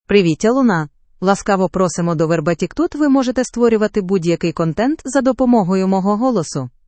FemaleUkrainian (Ukraine)
Luna — Female Ukrainian AI voice
Luna is a female AI voice for Ukrainian (Ukraine).
Voice sample
Listen to Luna's female Ukrainian voice.